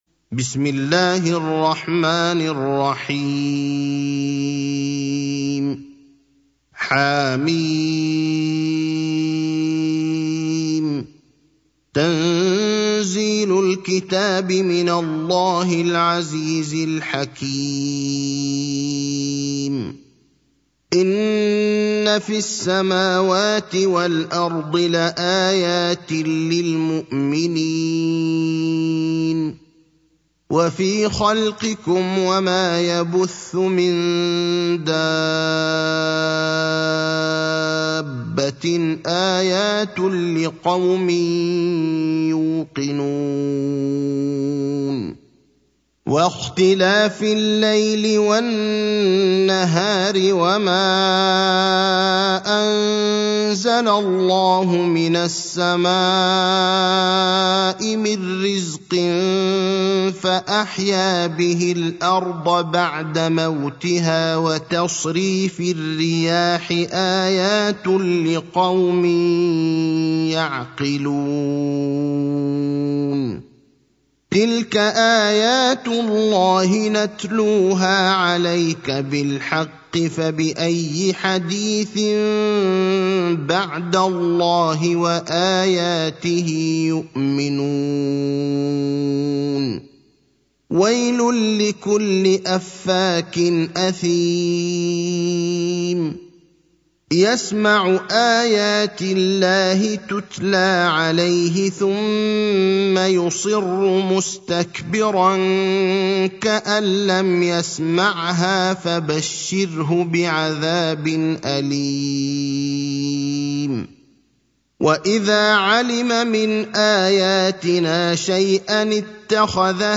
المكان: المسجد النبوي الشيخ: فضيلة الشيخ إبراهيم الأخضر فضيلة الشيخ إبراهيم الأخضر الجاثية (45)